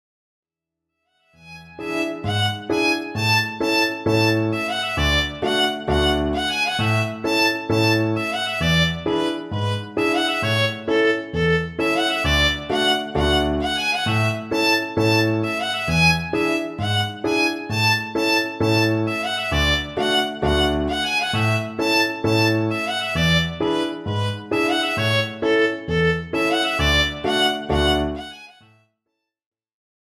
Besetzung: Viola